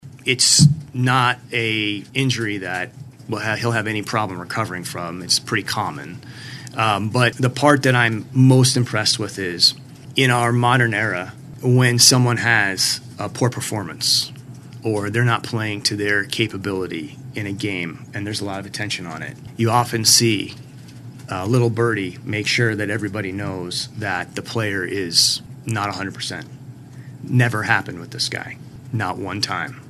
General Manager Sam Presti had his year-end press conference on Monday and talks about the injury.